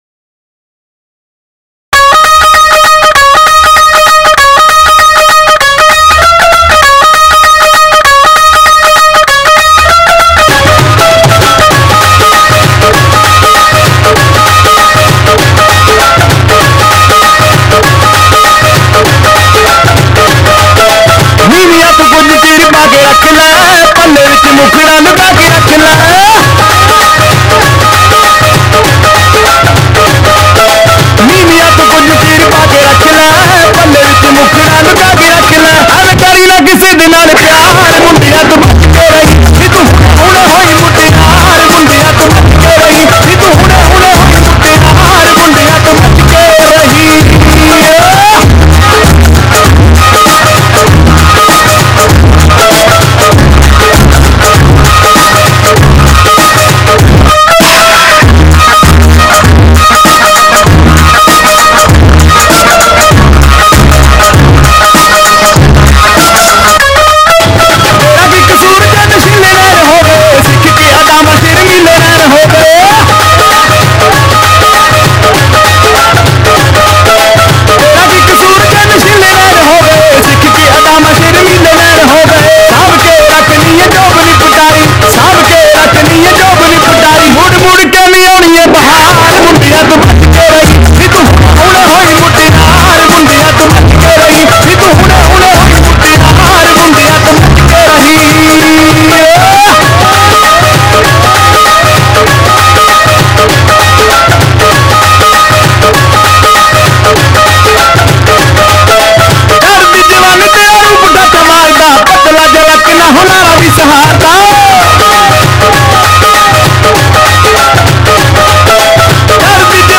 Loud Indian Music